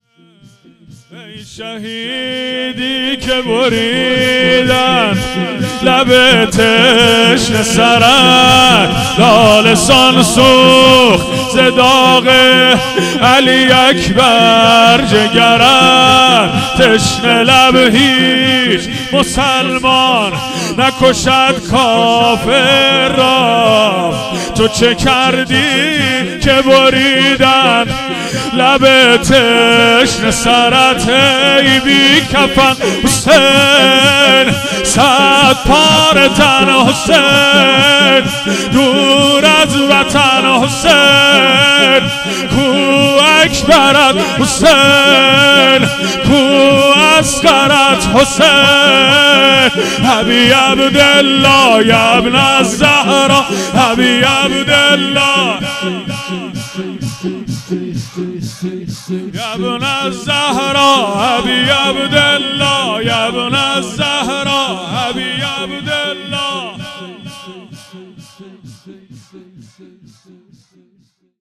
شور | ای شهیدی که بریدن
مجلس شهادت امام صادق (ع)